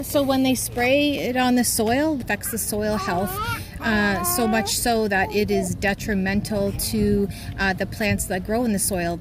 The Ministry of Natural Resources office on Riverside Drive in Pembroke was the site of a rally on Tuesday afternoon to stop the spray of herbicides, namely glyphosate, on Renfrew County forests.